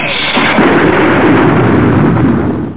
Lightnin.mp3